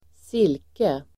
Ladda ner uttalet
Folkets service: silke silke substantiv, silk Uttal: [²s'il:ke] Böjningar: silket Synonymer: siden Definition: (mjukt tyg av en) slags tunn och blank tråd silk , silke, siden , siden , siden, silke , silke